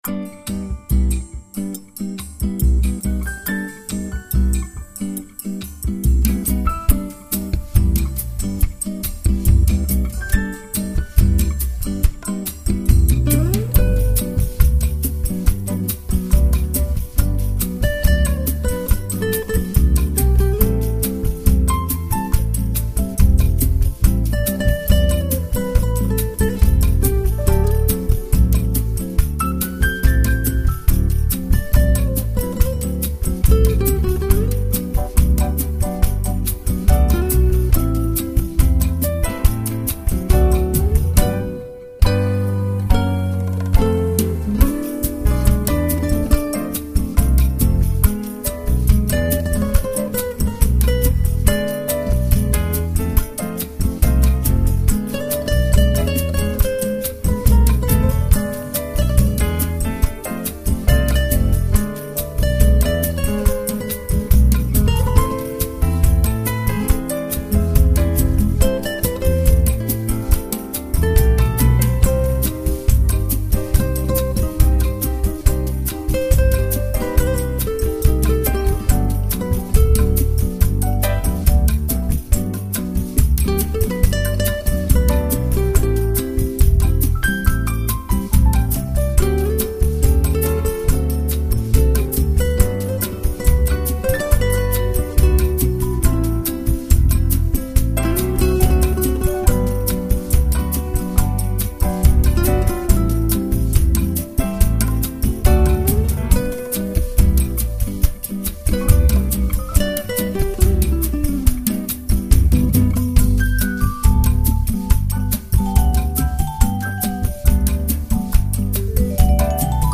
音乐类型：POP
轻柔浪漫的BOSSA NOVA，轻揉慢捻的吉他，